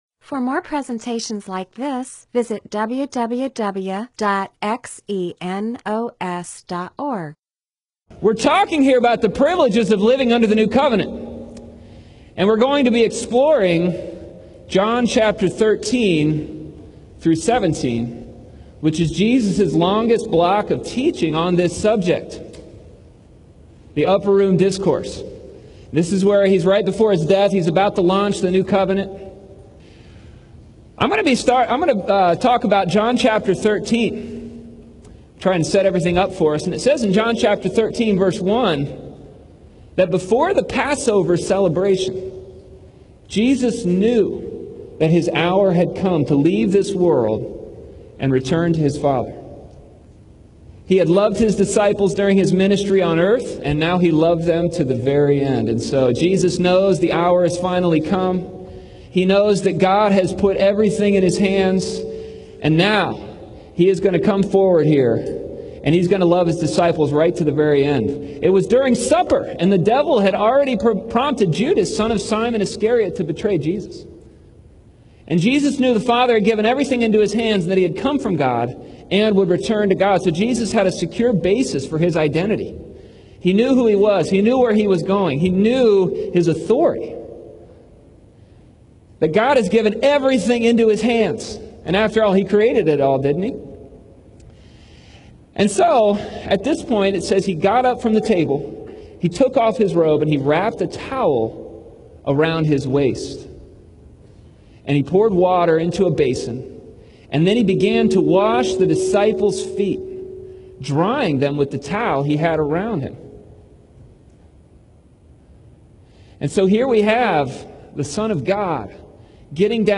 MP4/M4A audio recording of a Bible teaching/sermon/presentation about John 13:1-5; John 13:12-15; John 13:34-35.